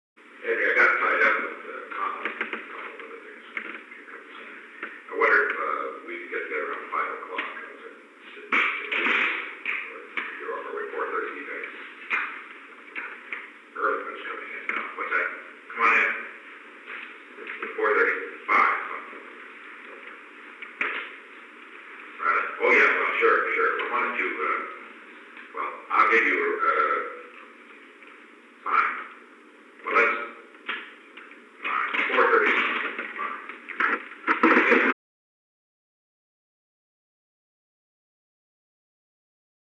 Conversation: 860-014
Recording Device: Oval Office
The Oval Office taping system captured this recording, which is known as Conversation 860-014 of the White House Tapes.
The President talked with Henry A. Kissinger.